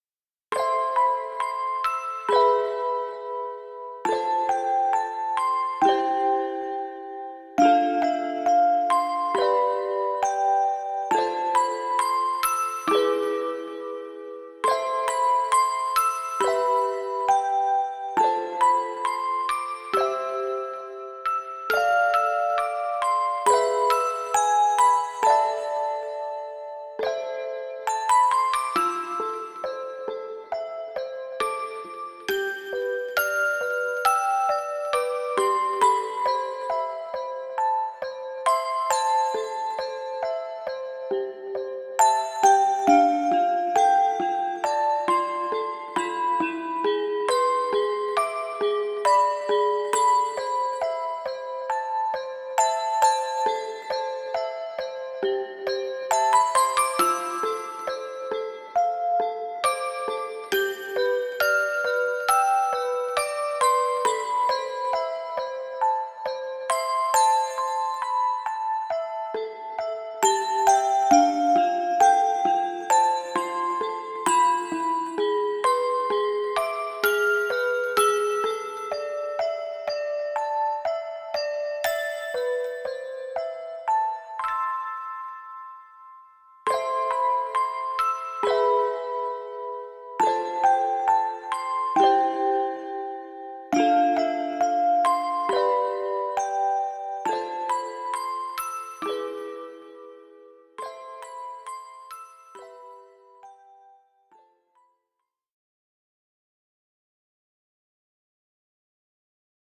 很悠扬的钢琴曲，谢谢分享！